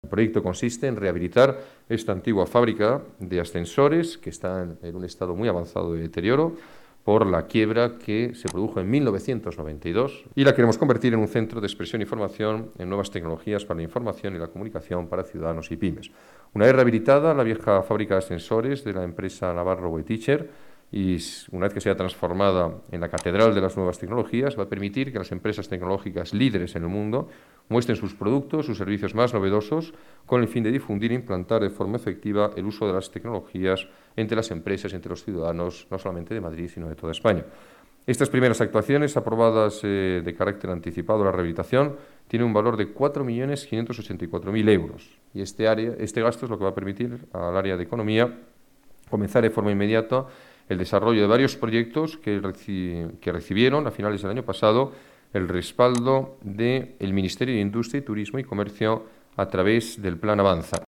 Nueva ventana:Declaraciones del alcalde, Alberto Ruiz-Gallardón: en marcha la 'Catedral de las Nuevas Tecnologías'